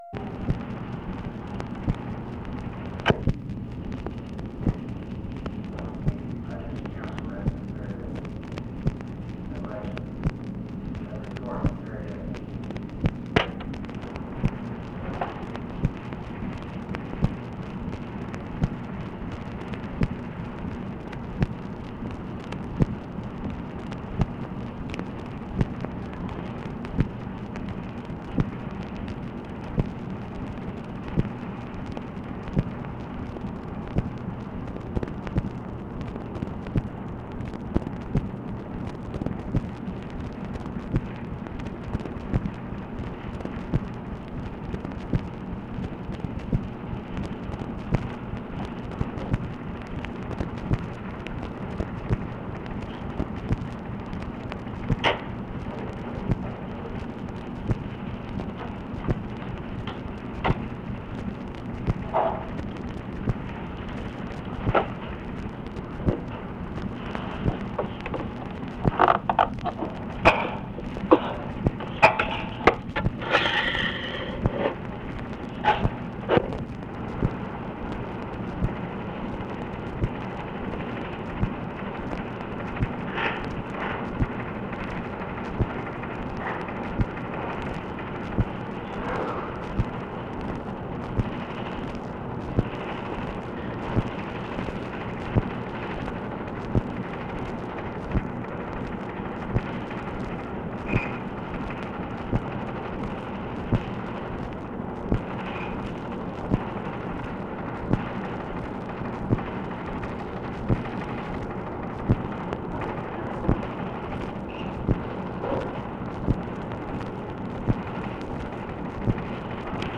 OFFICE NOISE, April 30, 1965
Secret White House Tapes | Lyndon B. Johnson Presidency